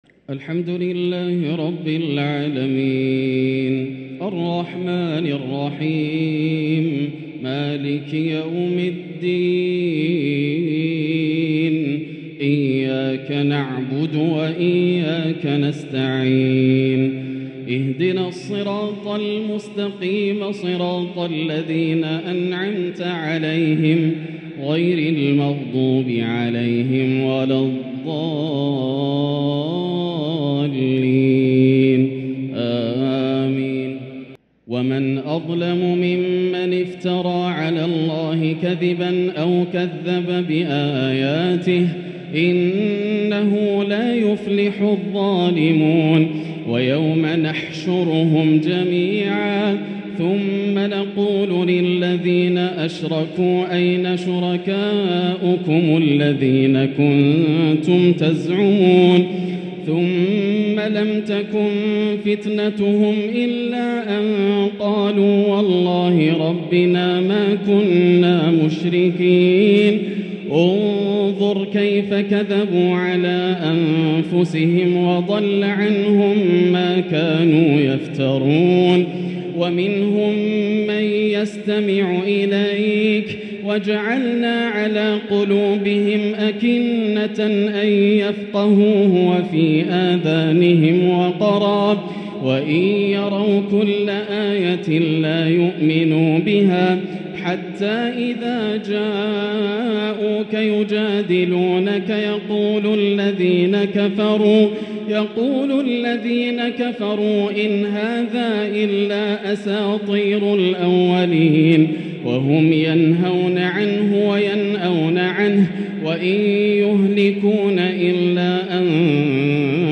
تراويح ليلة 9 رمضان 1444هـ من سورة الأنعام (21-73) > الليالي الكاملة > رمضان 1444هـ > التراويح - تلاوات ياسر الدوسري